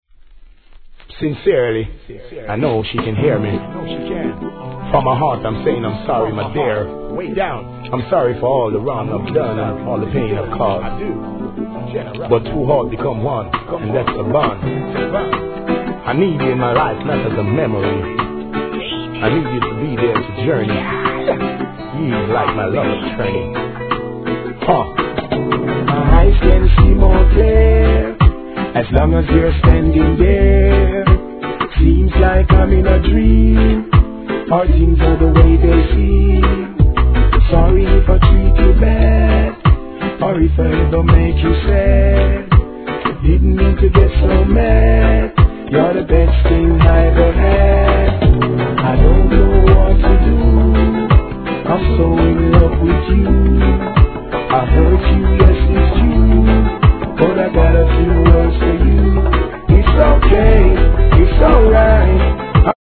REGGAE
ミディアムの超BIG HITリディム!!